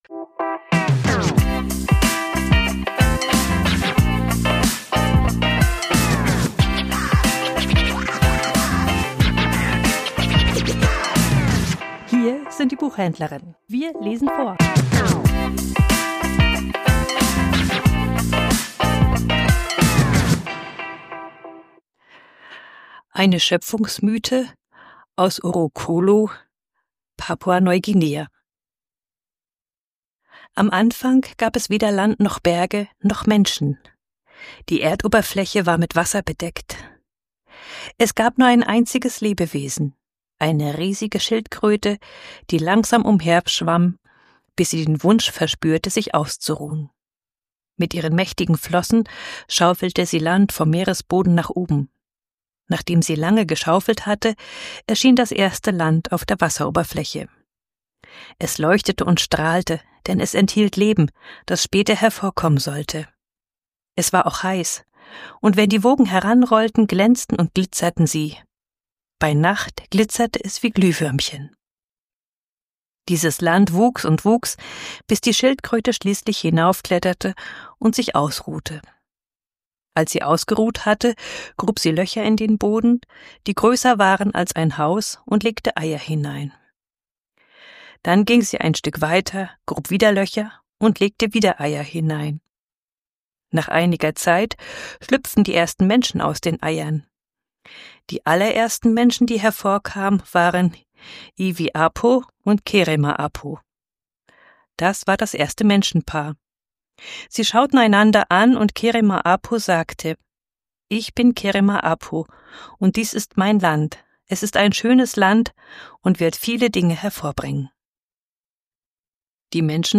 Vorgelesen: Schöpfungsmythe aus Papua-Neuguinea